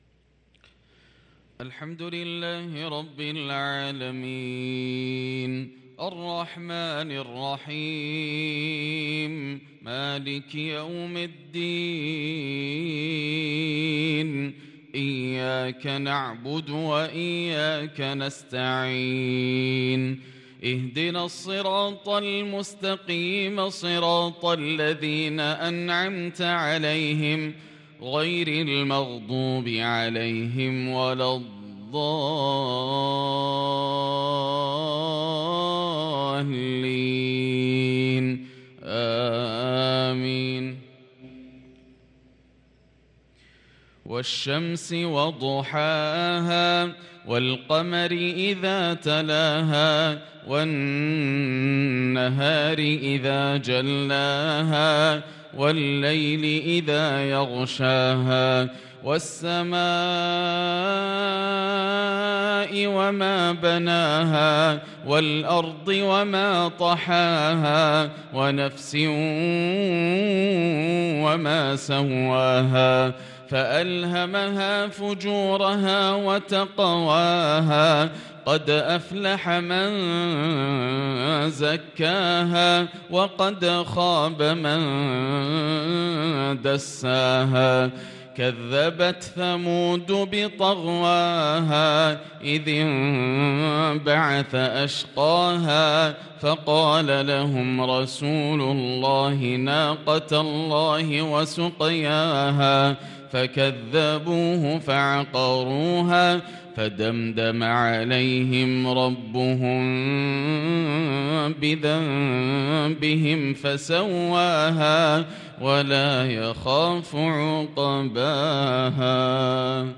صلاة المغرب للقارئ ياسر الدوسري 21 شوال 1443 هـ